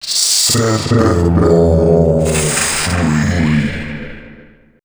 036 male.wav